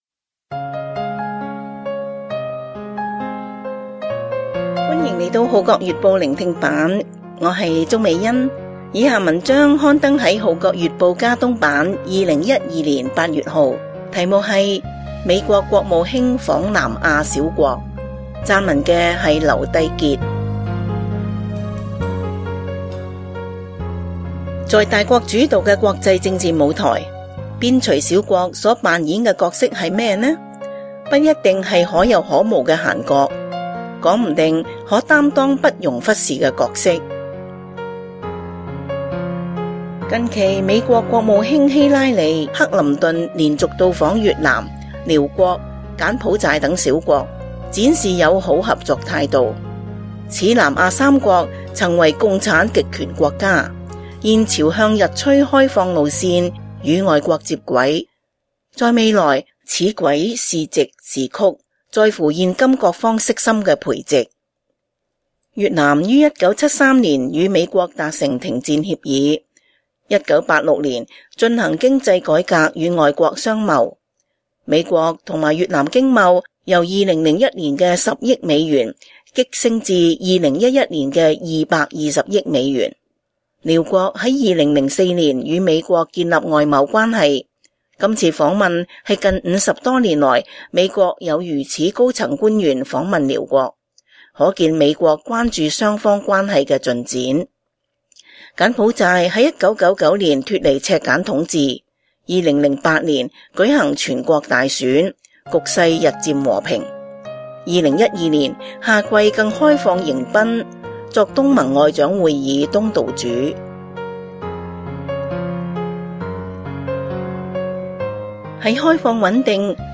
聆聽版/Audio美國國務卿訪南亞小國